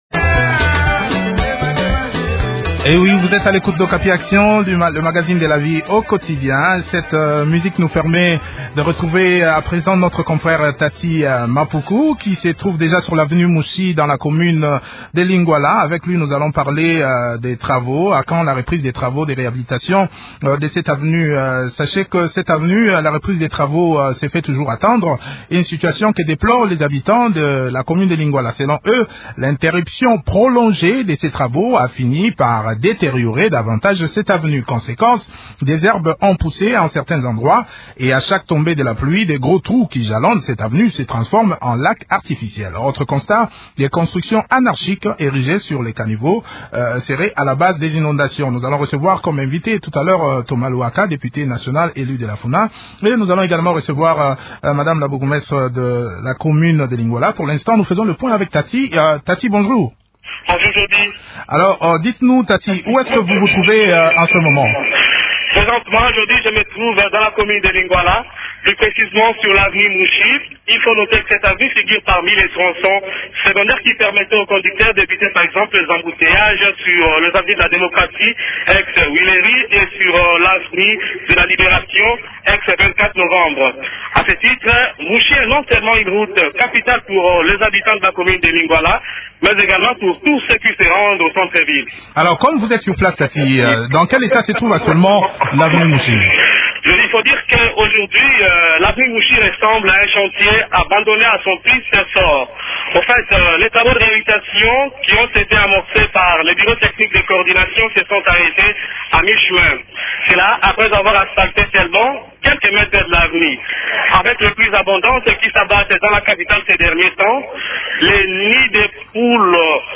en parle avec Thomas Luhaka, député national élu de la Funa et Brigitte Mwaluke Kembe, bourgmestre de la commune de Lingwala.